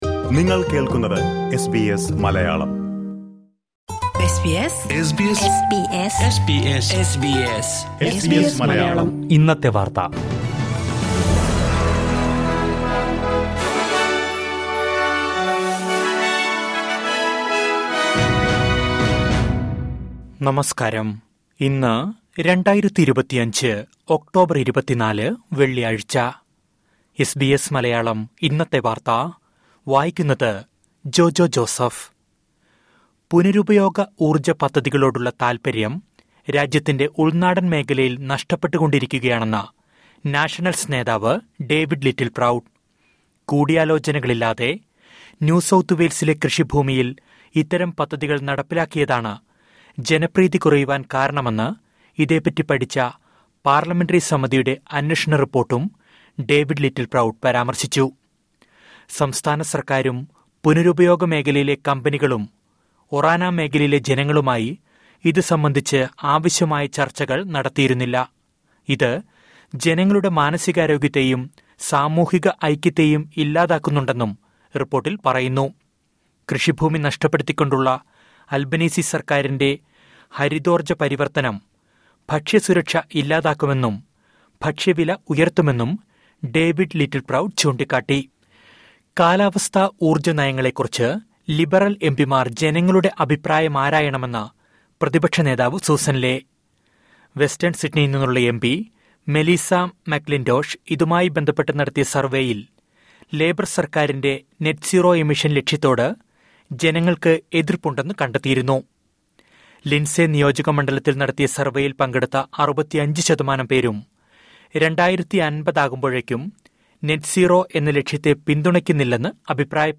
2025 ഒക്ടോബർ 24ലെ ഓസ്ട്രേലിയയിലെ ഏറ്റവും പ്രധാന വാർത്തകൾ കേൾക്കാം...